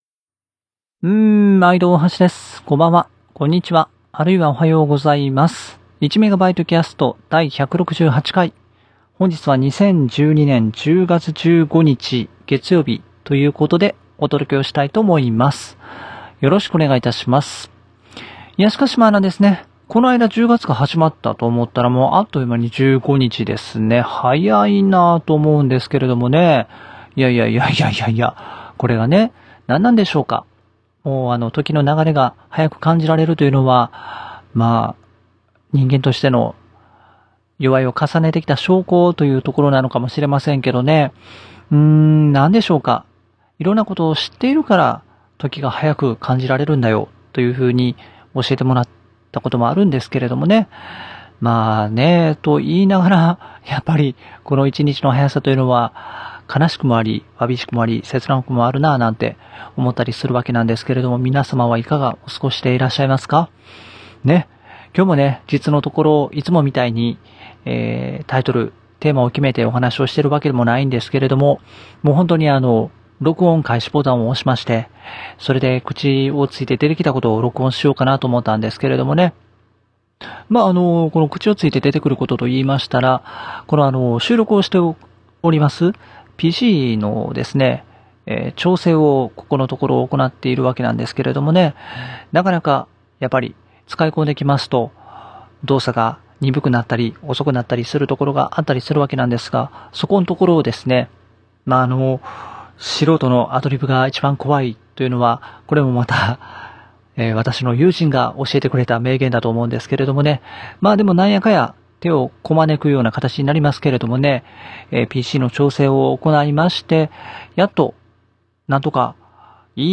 【今回の収録環境：メインPC+オーディオキャプチャー(UA-4FX)+コンデンサマイク(C05)】